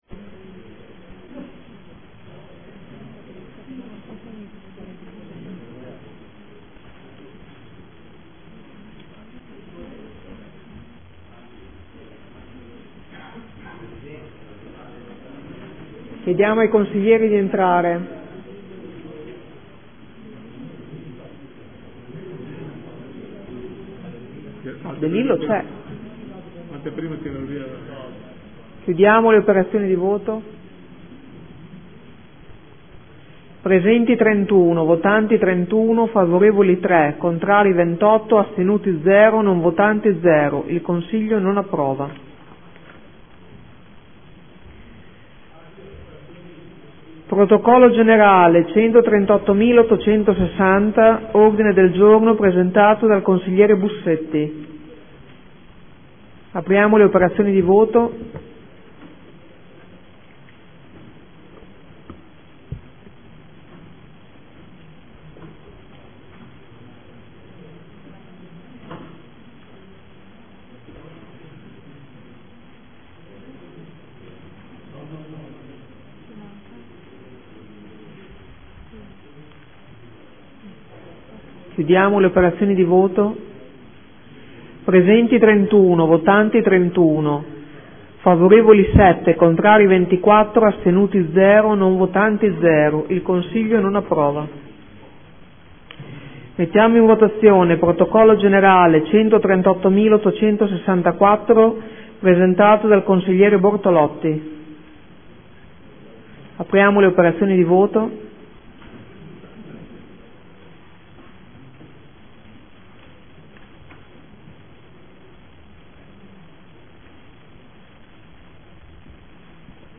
Seduta del 3/11/2014. Mette ai voti gli ordini del giorno.